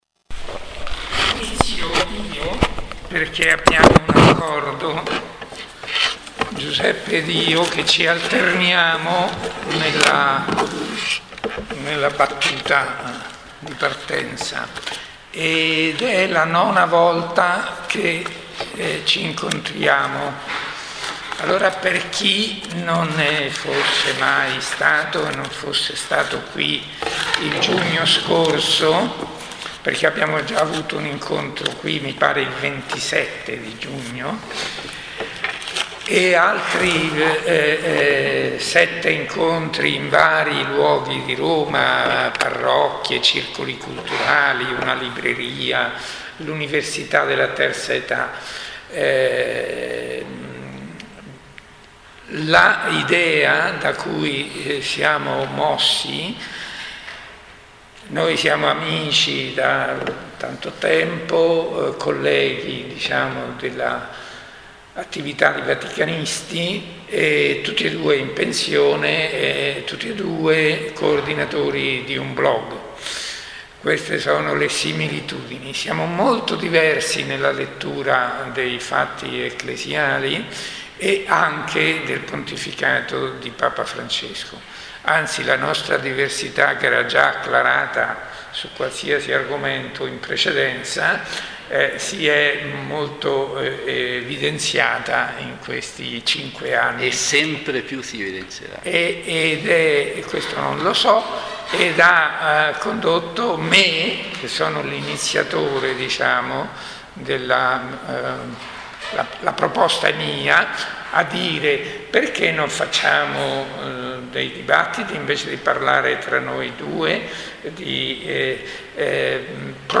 Stavolta torniamo all’Università La Sapienza dov’eravamo stati in giugno.
Ecco di seguito la registrazione audio dell’incontro.